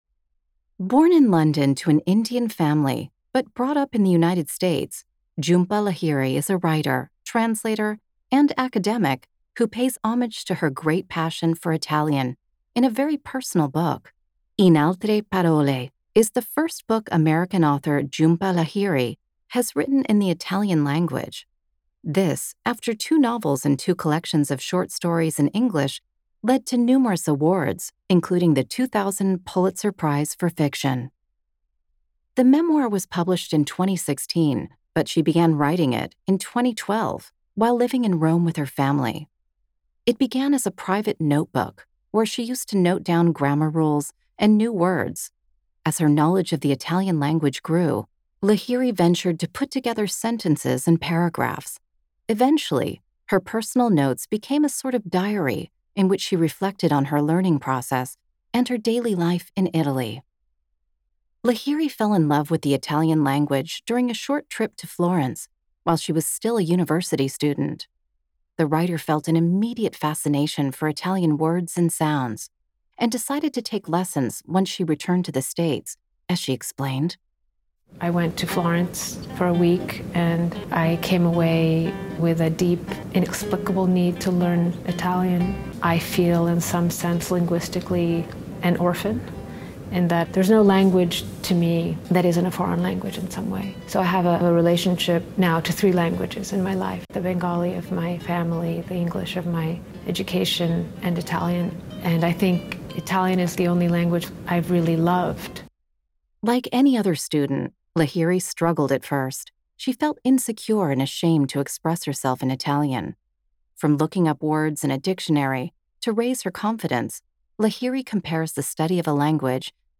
USAx2
Speaker (American accent)